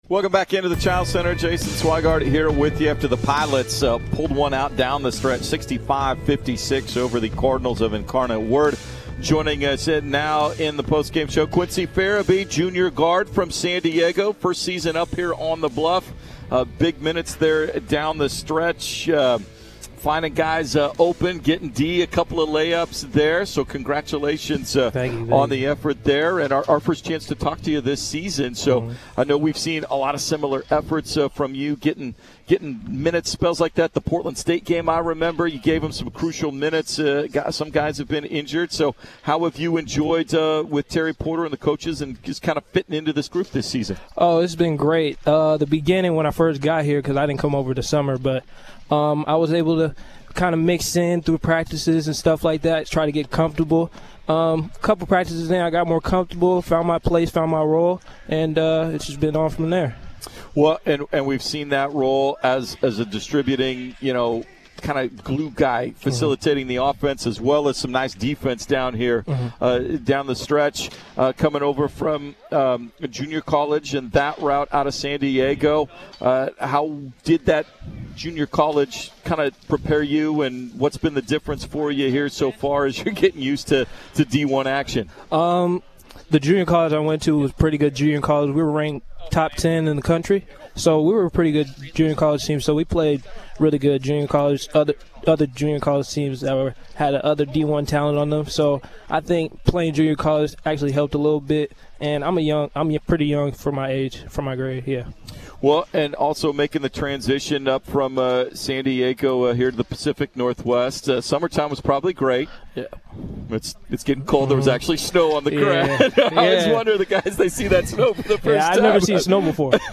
Men's Hoops Post-Game Interviews vs. Incarnate Word